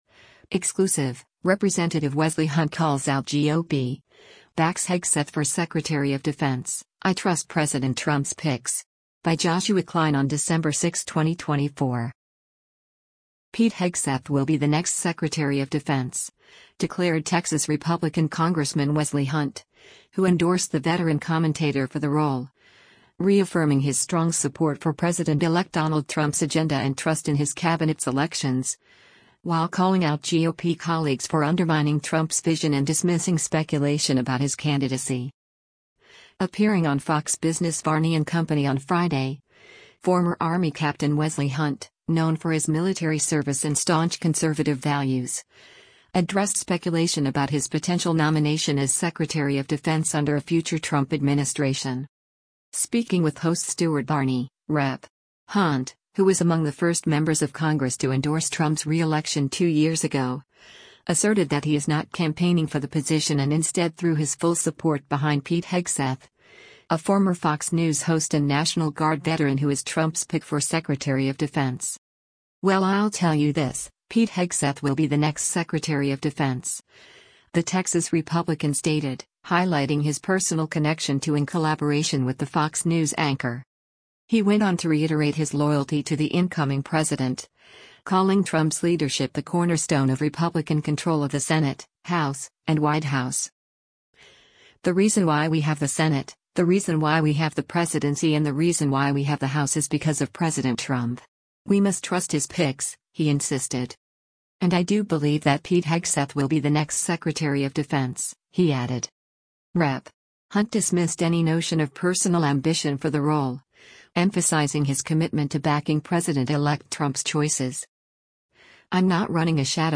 Appearing on Fox Business’ Varney & Co. on Friday, former Army Captain Wesley Hunt, known for his military service and staunch conservative values, addressed speculation about his potential nomination as Secretary of Defense under a future Trump administration.